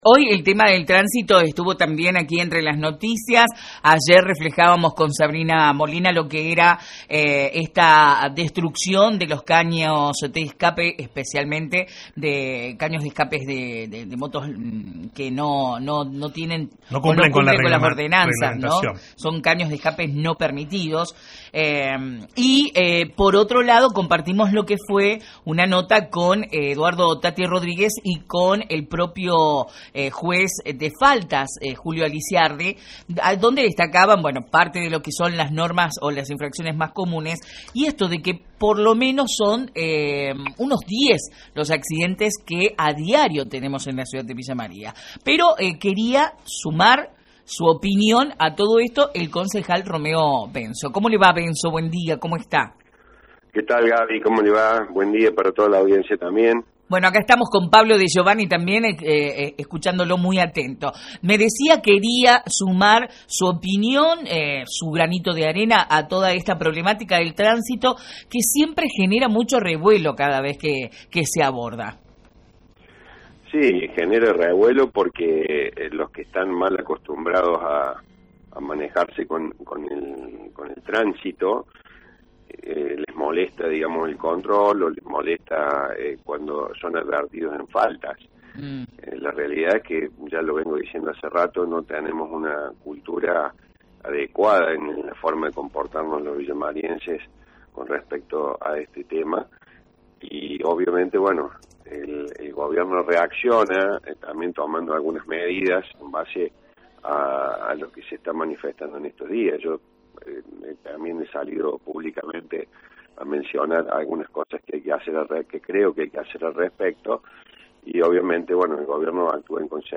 Escuche la charla generada con los conductores de «La Mañana Informal».